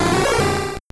Cri de Pharamp dans Pokémon Or et Argent.